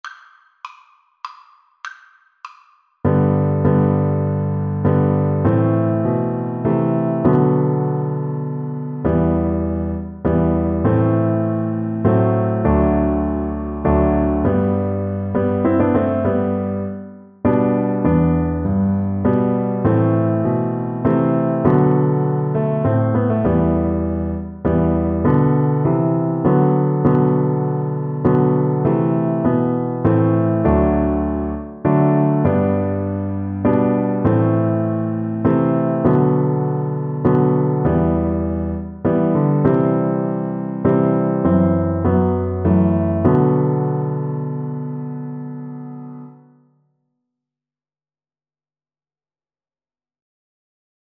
Play (or use space bar on your keyboard) Pause Music Playalong - Piano Accompaniment Playalong Band Accompaniment not yet available transpose reset tempo print settings full screen
Viola
G major (Sounding Pitch) (View more G major Music for Viola )
Con moto
3/4 (View more 3/4 Music)
B4-B5
Traditional (View more Traditional Viola Music)